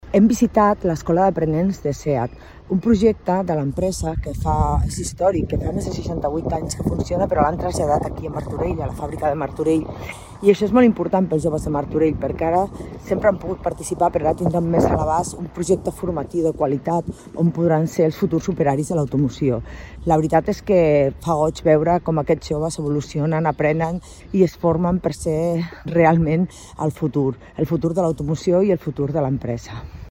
Rosa Cadenas, regidora Promoció Econòmica